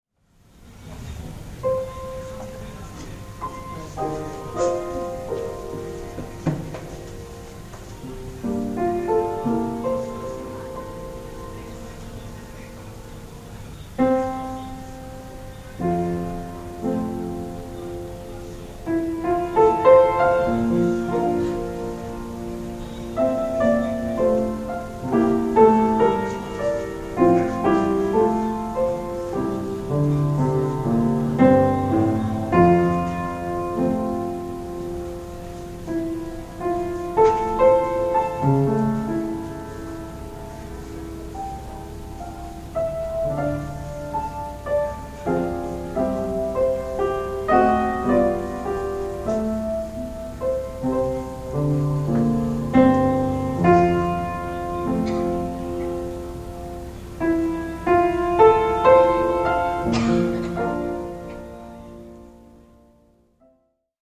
ピアノ